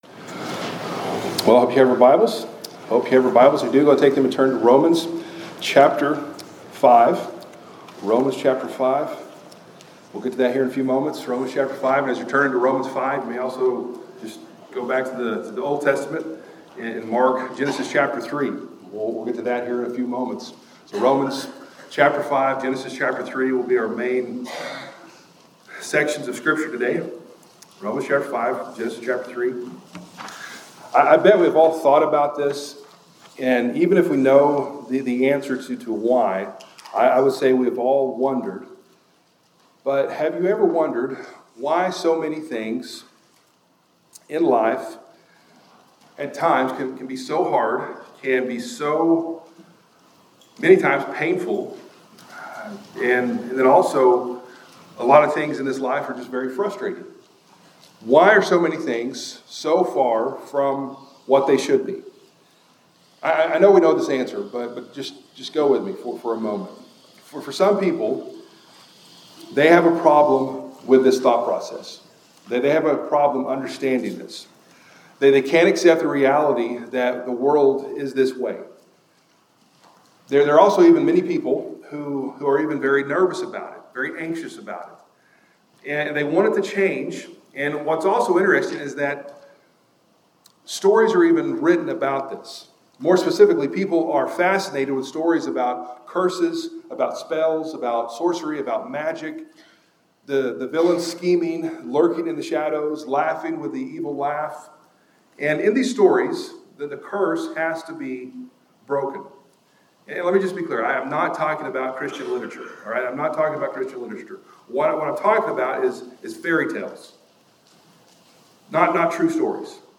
Sermon Playlist Family Resources